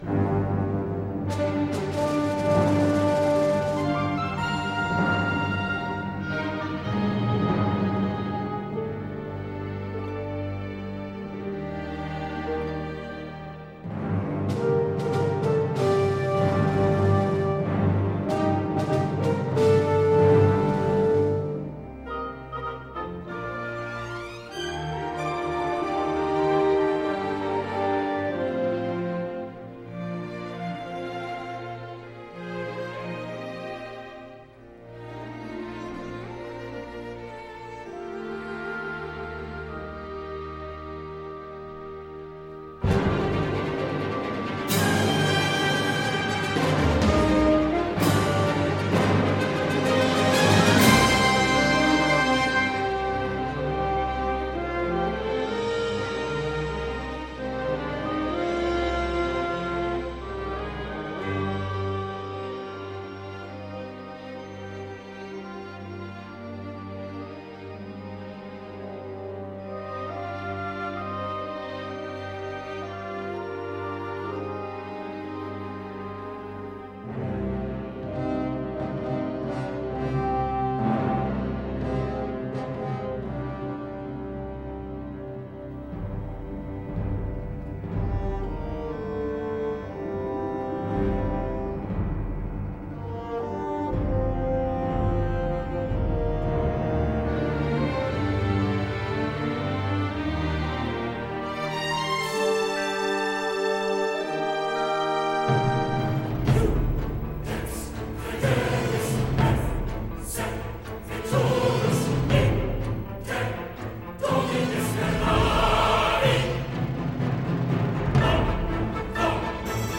Grandiloquent mais grandiose.